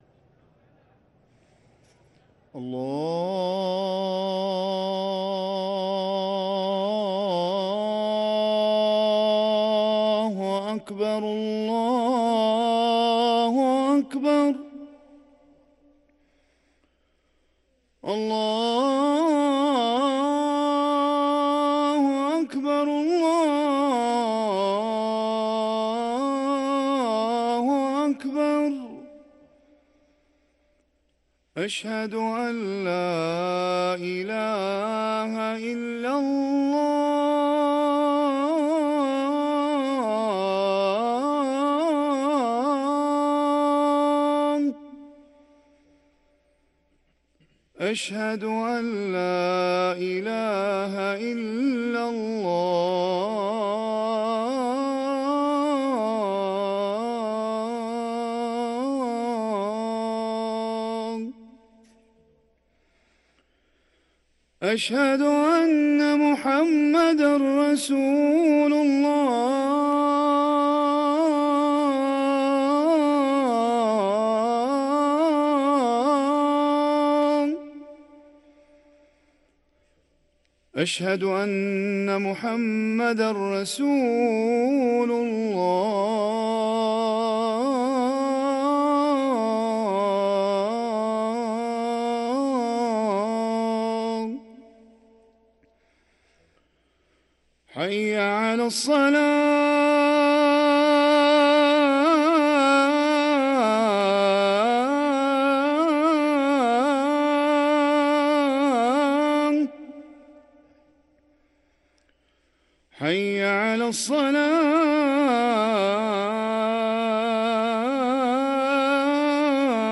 أذان الفجر
ركن الأذان